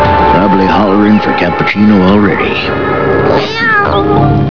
Dr. Briefs mumbling about a cappuchino machine and Tama-Sama speaking!